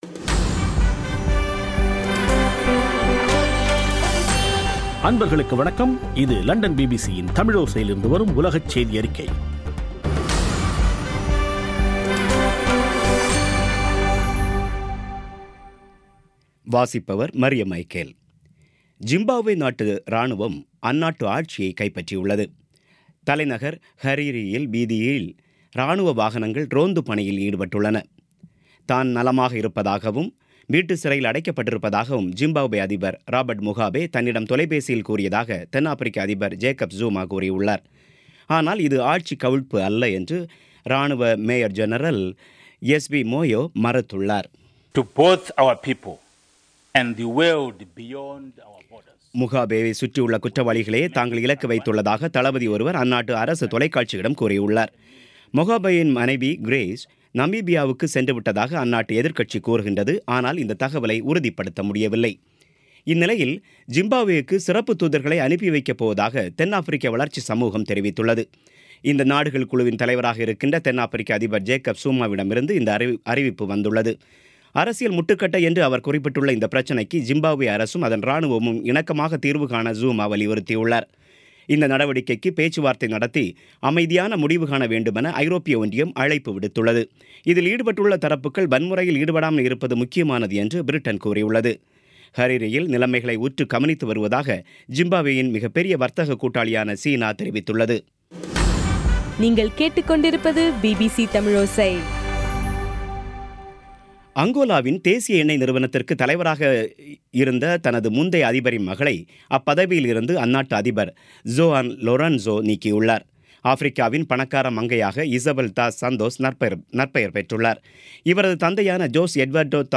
பிபிசி தமிழோசை செய்தியறிக்கை (15/11/2017)